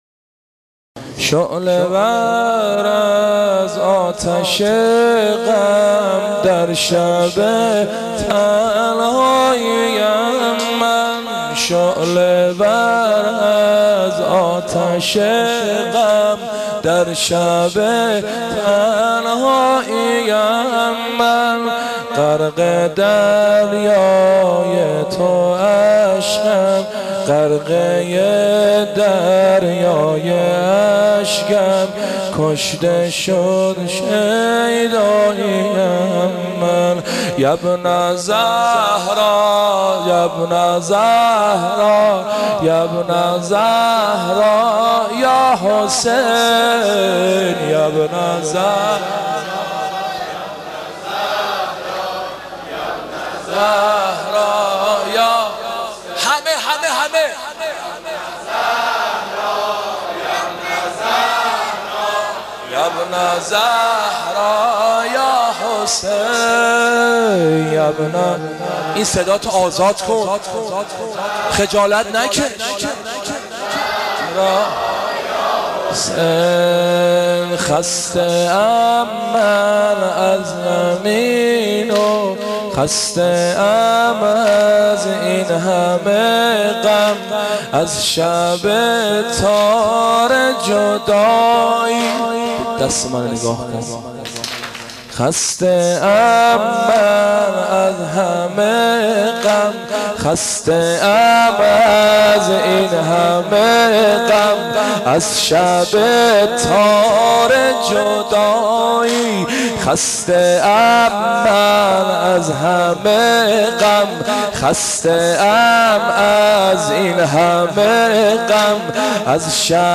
مداحی حاج حسین سیب سرخی به مناسبت شهادت امام موسی کاظم(ع)